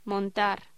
Locución: Montar